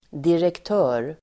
Uttal: [direkt'ö:r]